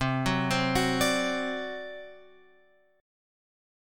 C Suspended 2nd Flat 5th